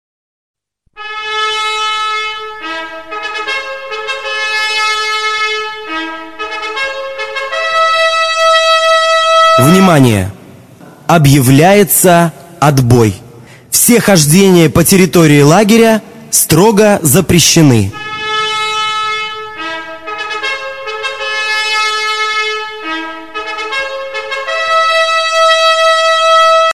5 станция. Символика пионерии – Горн, барабан.
Давайте послушаем примеры звучания сигналов горна и барабана.
отбоя, сбор на линейку и пионерский марш.
pionerskiy_gorn_-_otboy.mp3